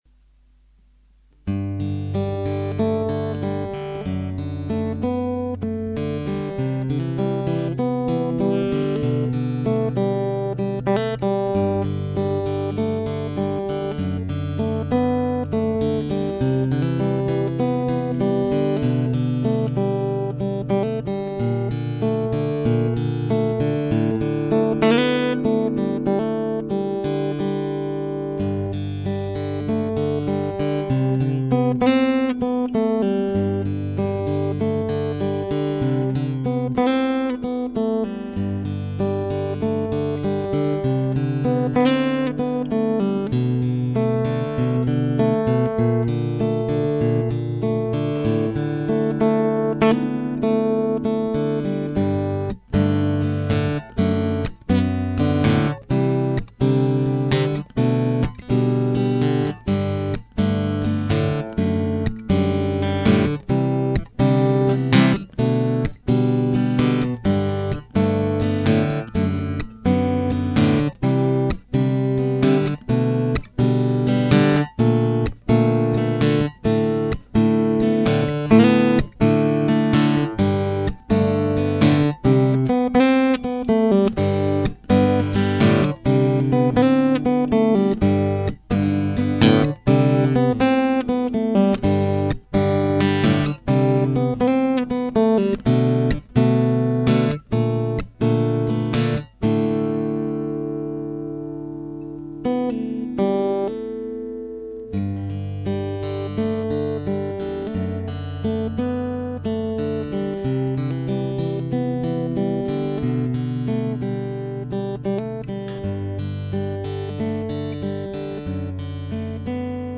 Mis dedos son tocones de madera cuando empuño la guitarra.
El otro día me di cuenta de que la guitarra estaba hablando sola, y hablaba de ti.